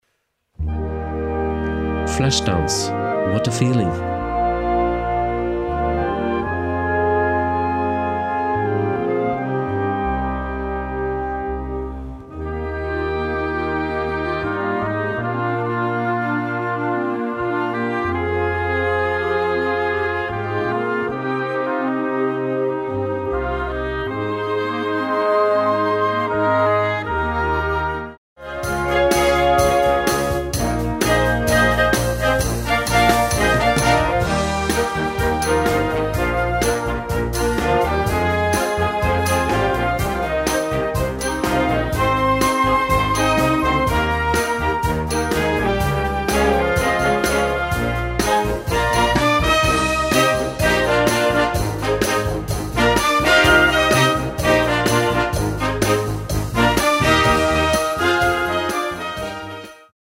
Gattung: Modernes Jugendwerk
Besetzung: Blasorchester